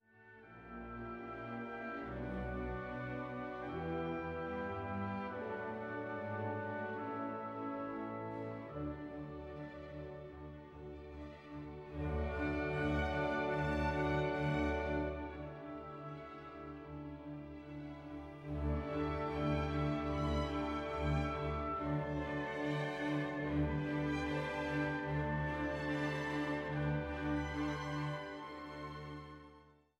Allegro con spirito